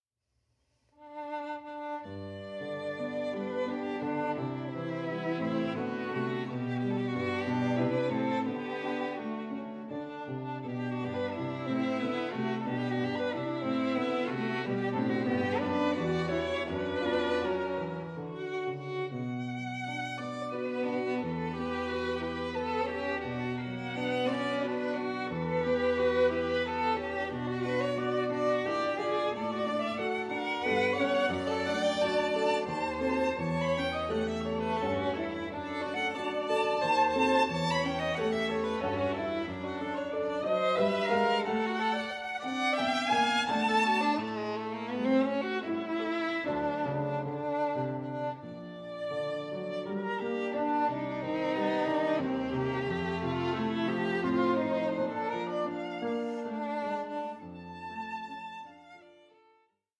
Allegro moderato 5:30